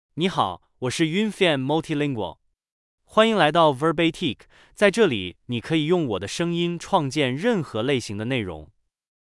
MaleChinese (Mandarin, Simplified)
Yunfan Multilingual is a male AI voice for Chinese (Mandarin, Simplified).
Voice sample
Listen to Yunfan Multilingual's male Chinese voice.